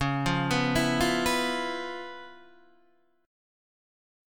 C#7#9 chord {9 8 9 9 6 7} chord